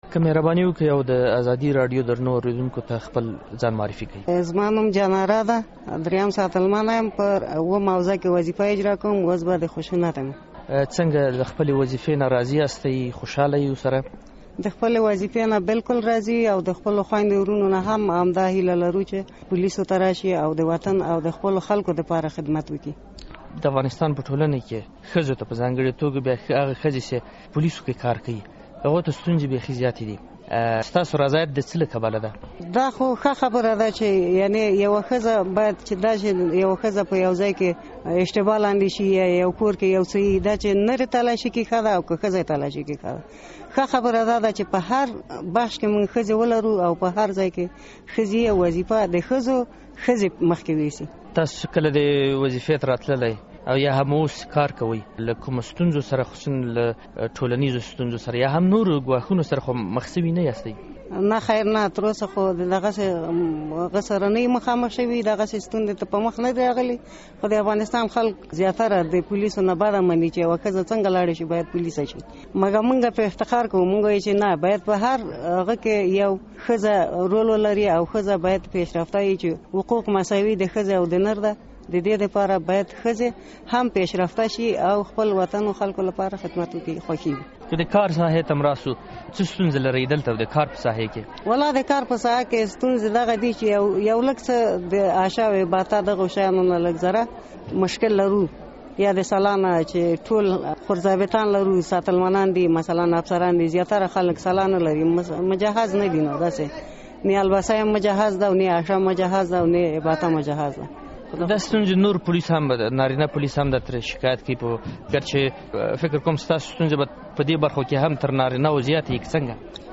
له یوې پولیسې سره مرکه